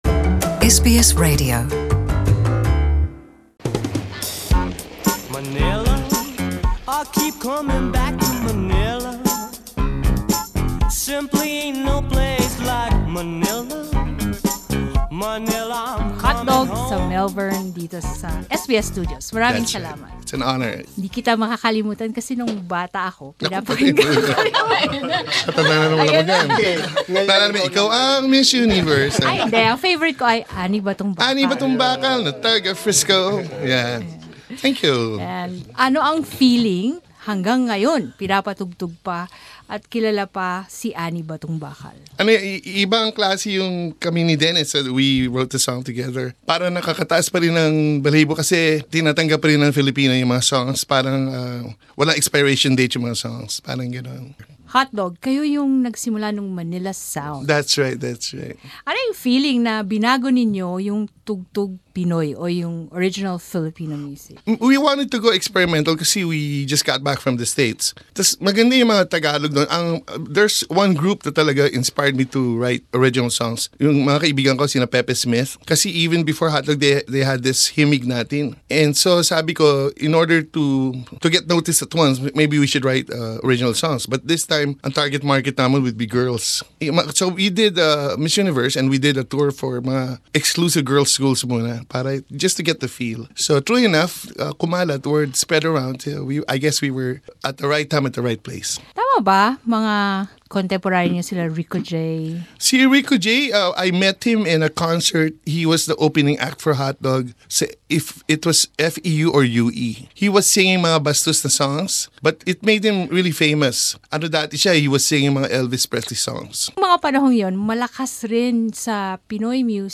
Hotdog's Rene Garcia passed away on September 2 at the age of 65. Listen in to his 2014 interview, during his visit to SBS Radio.
Rene Garcia with the Hotdog band at the SBS Radio Studio in Melbourne in 2014 Source: SBS Filipino 2014